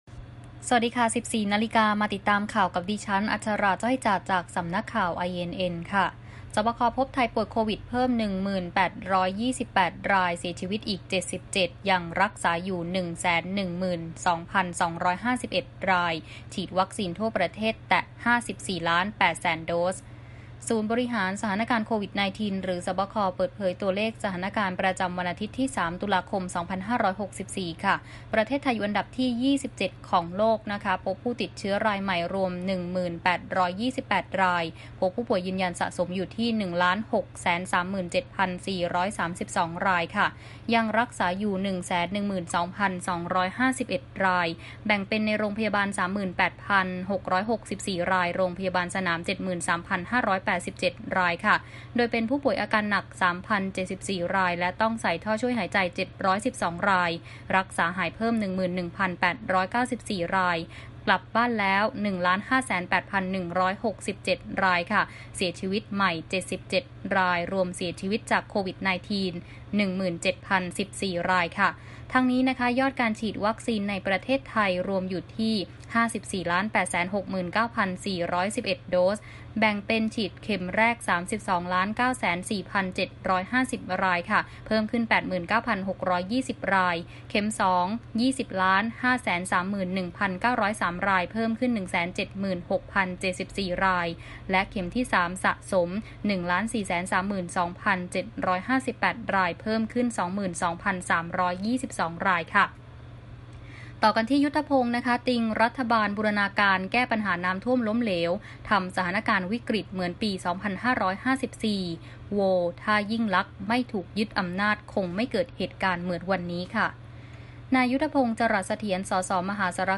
ข่าวต้นชั่วโมง 14.00 น.